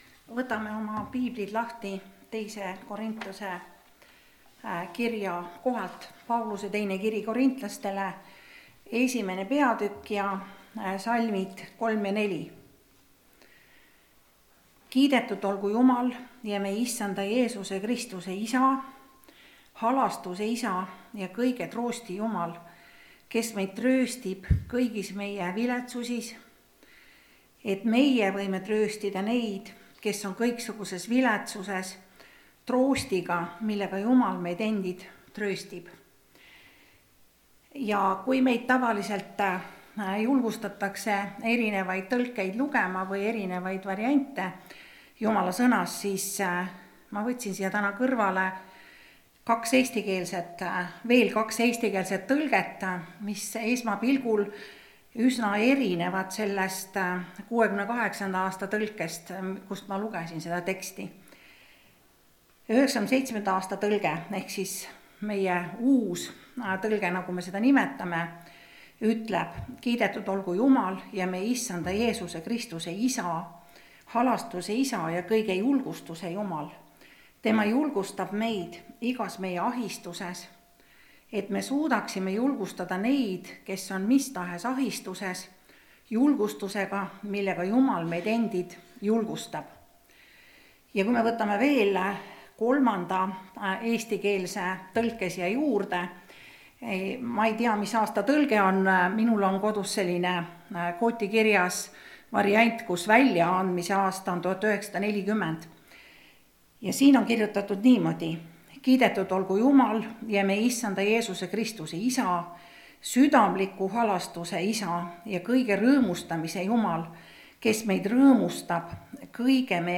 (Rakveres)
Jutlused